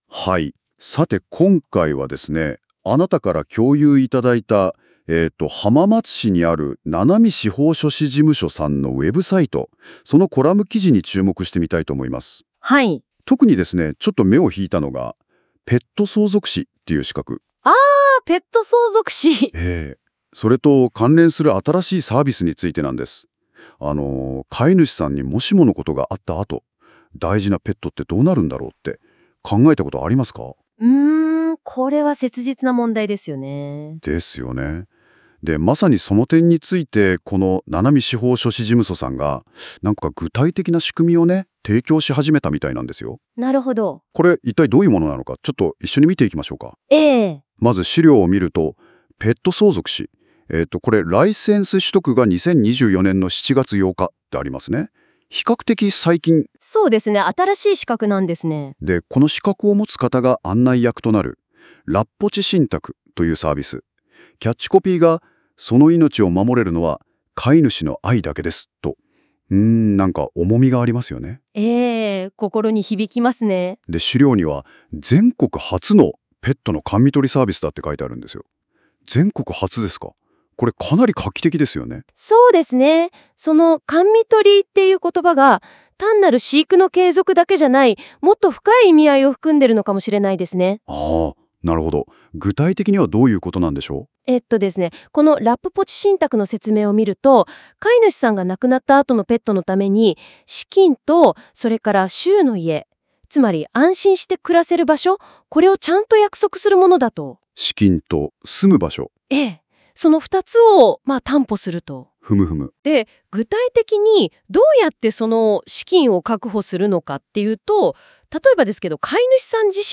【音声によるダイジェスト版】 ラジオ番組のようなやり取りで聞きやすい音声でのダイジェスト版です。